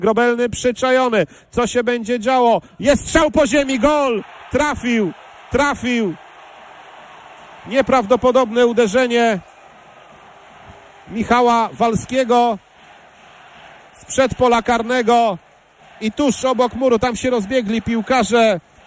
1ah8z2txwg4g7nm_puszcza-warta-gol.mp3